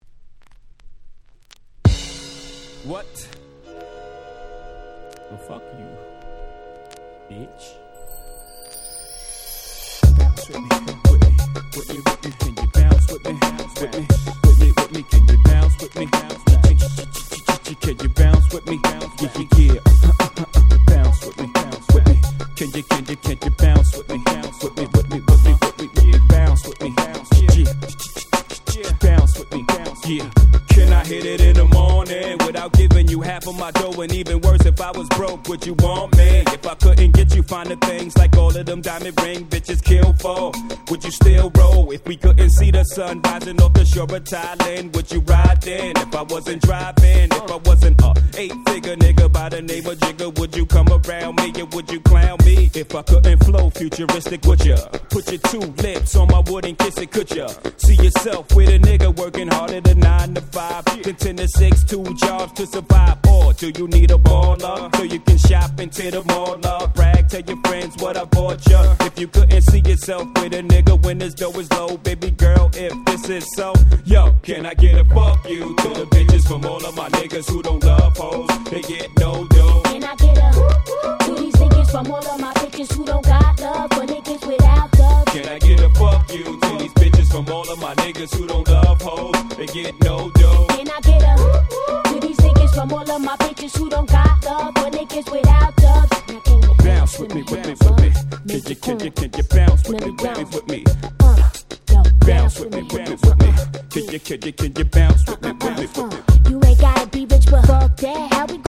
98' Super Hit Hip Hop !!